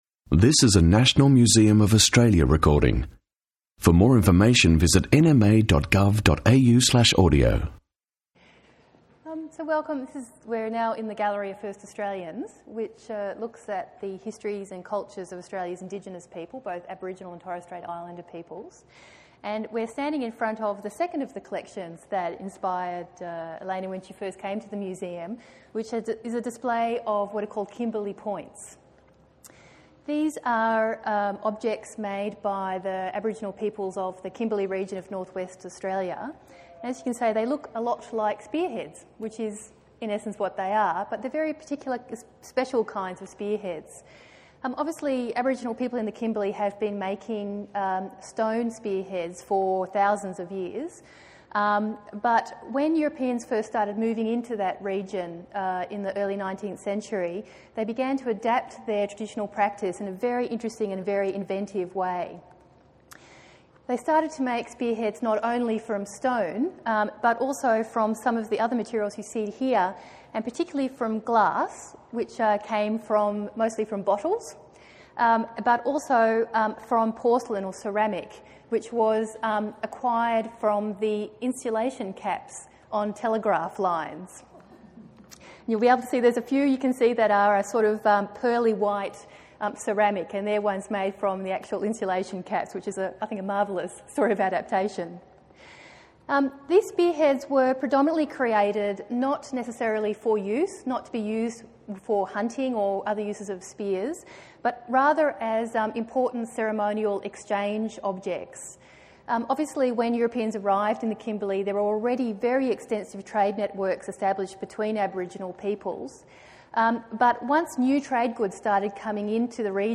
Open player in a new tab Presenters: Elena Kats-Chernin Tags: arts first nations 00:00 / 28:12 Download Kimberley points performance and talk with Elena Kats-Chernin audio file (12.9 MB) View transcript